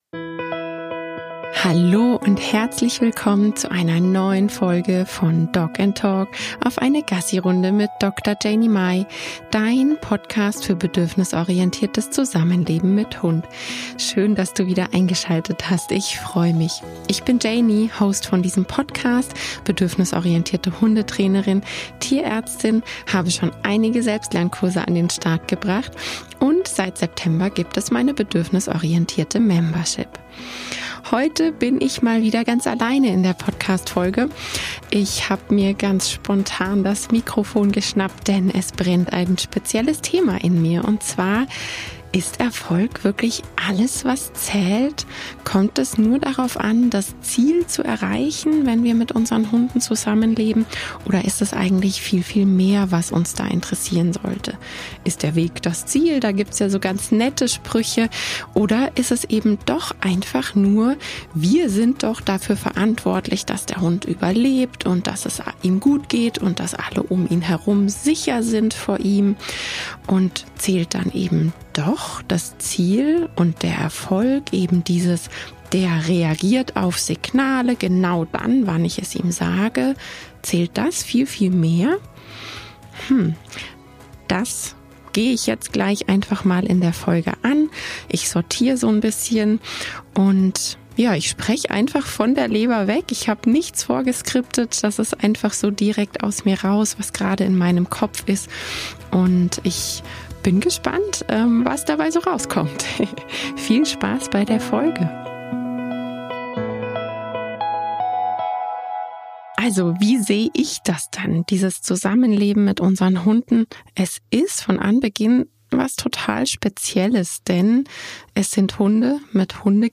In dieser Folge bin ich mal wieder ganz allein und spontan einer Frage nachgegangen, die mich zur Zeit beschäftigt: ist wirklich nur das Erreichen eines bestimmten Ziels alles was zählt, oder sollten wir vielmehr auch dem Weg dorthin unsere Aufmerksamkeit und Wertschätzung schenken?